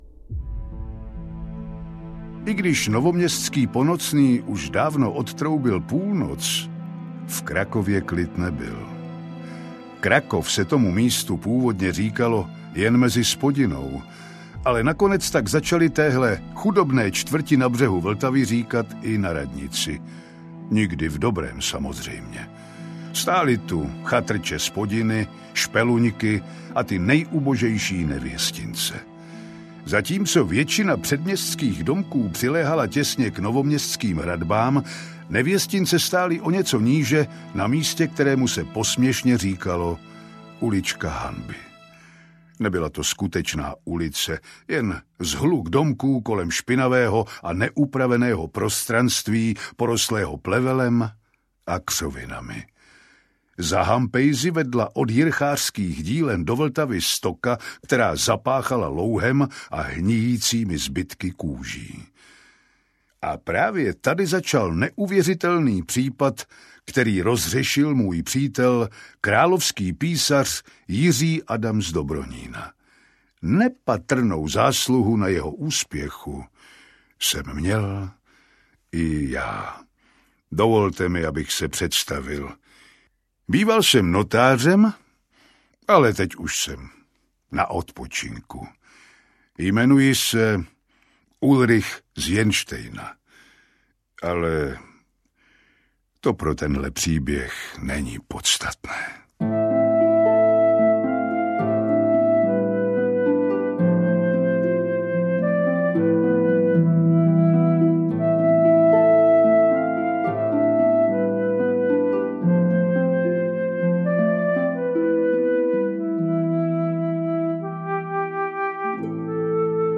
Audiokniha Ulička hanby, kterou napsal Vlastimil Vondruška, patří do cyklu Letopisy královské komory - dramatizace historického detektivního románu, který se odehrává v polovině 16. století.
Ukázka z knihy